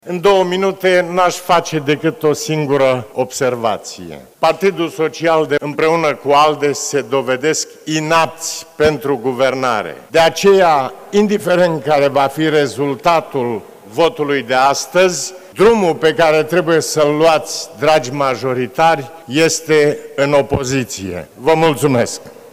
Senatorul PMP, Traian Băsescu a avut la dispoziție doar două minute la tribuna Parlamentului, în ședința comună de dezbatere a moțiunii de cenzură împotriva Guvernului Grindeanu.